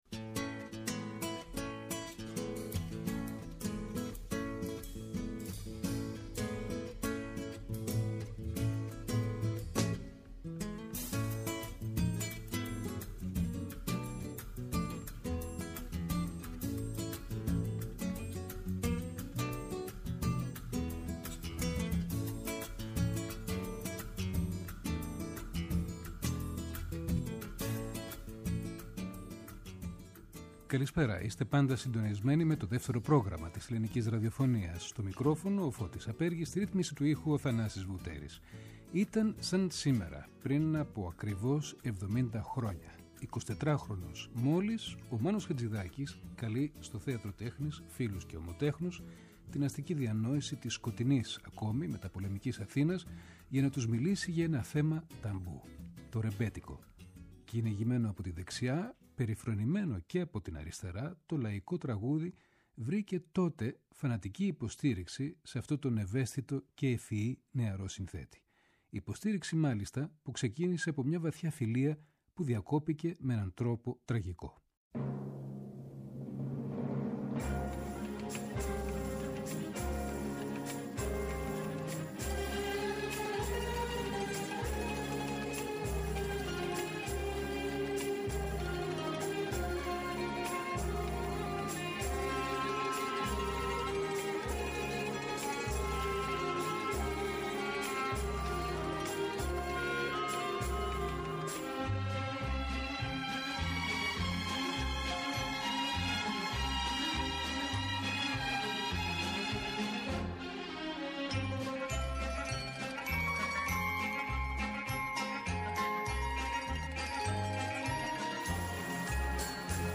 Ο Χατζιδάκις μιλά για το ρεμπέτικο στην τελευταία του συνέντευξη